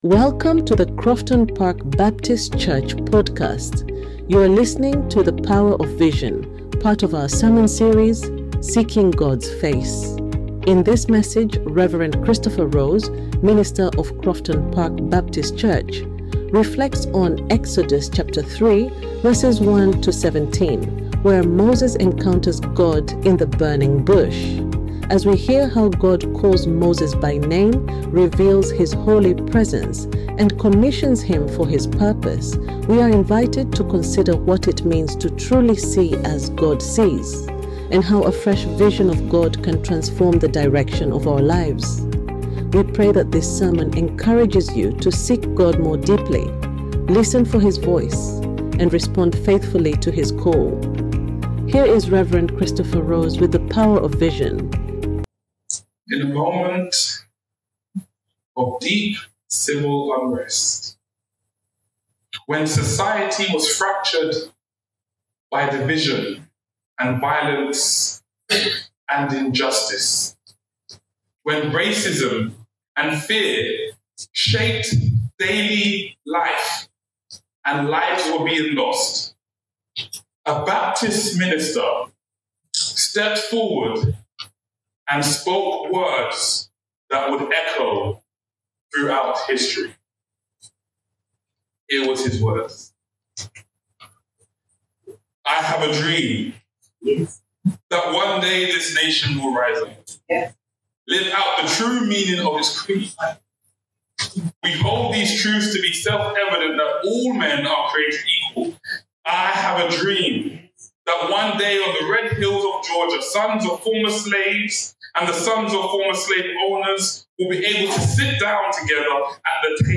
You are listening to The Power of Vision, part of our sermon series, Seeking God’s Face.